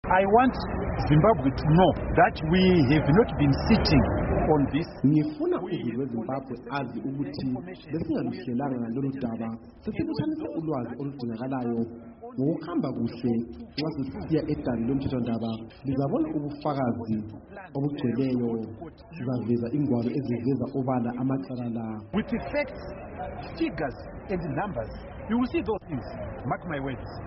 Ekhuluma ngesikhathi kugcotshwa abahluleli eHarare, umgcinisihlalo weZACC uMnu Michael Reza utshele iStudio7 ukuthi sebebhalele osomabhizimusi laba ukuthi baze emahofisini abo, njalo bengehluleka bazabalanda.
Ingxoxo loMnu Michael Reza.